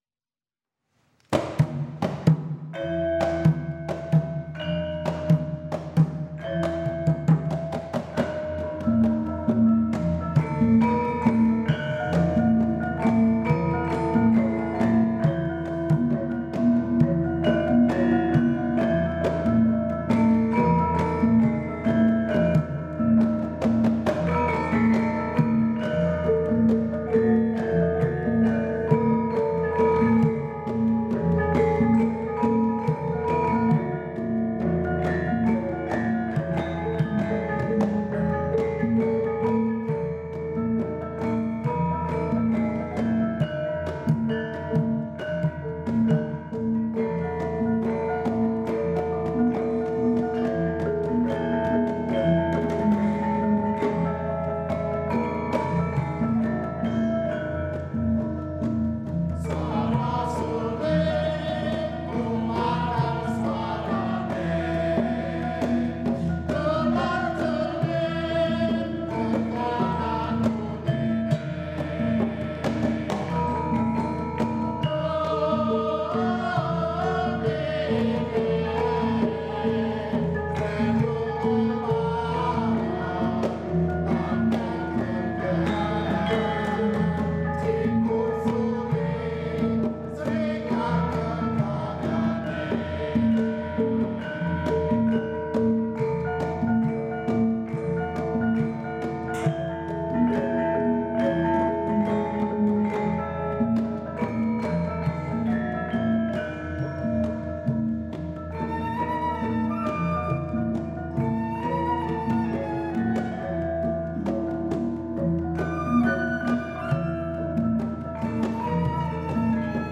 Dolanan Swara Suling laras pelog pathet nem, by Ki Nartosabdho (1959?)
Although the piece was not originally performed with a western transverse flute, we feel sure that Bapak Nartosabdho would enjoy the addition.